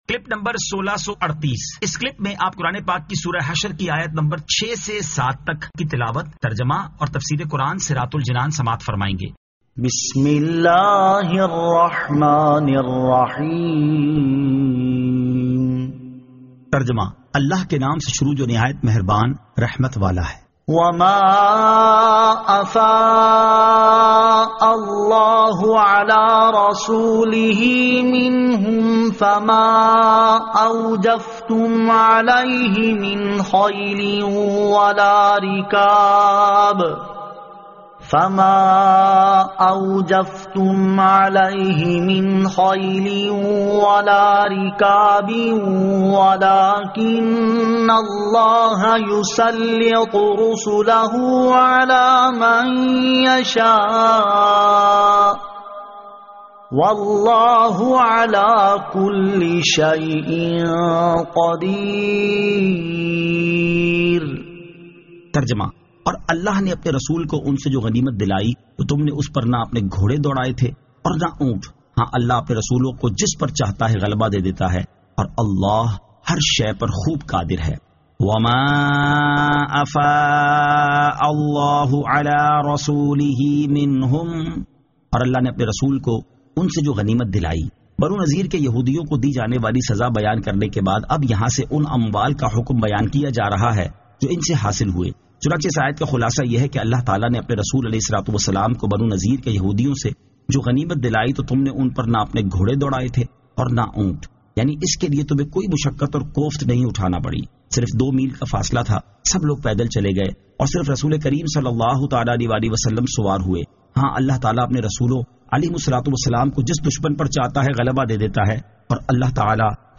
Surah Al-Hashr 06 To 07 Tilawat , Tarjama , Tafseer